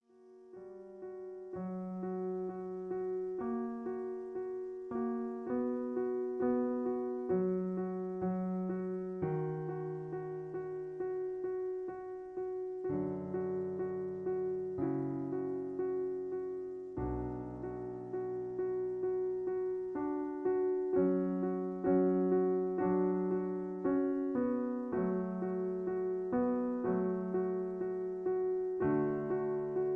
Original Key (B). Piano Accompaniment